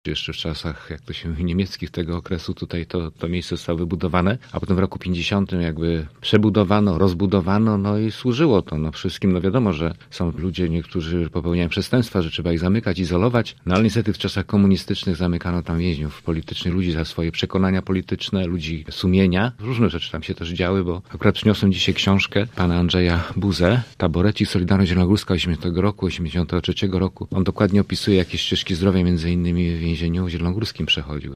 Areszt śledczy to miejsce, które zaznaczyło się w stanie wojennym – mówił w Rozmowie Punkt 9 Marek Budniak, miejski radny PiS i pełnomocnik wojewody ds. kombatantów, osób represjonowanych i działaczy antykomunistycznych: